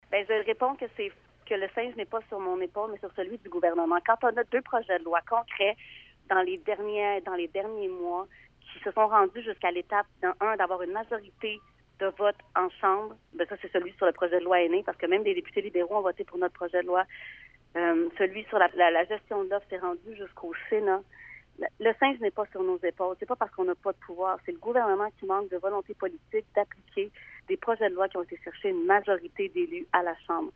L’événement a eu lieu jeudi matin au Centre d’interprétation de la nature du lac Boivin et elle en a profité pour dresser un bilan de l’actuelle campagne électorale.